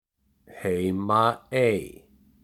Heimaey (Icelandic pronunciation: [ˈheiːmaˌeiː]
Heimaey_pronunciation.ogg.mp3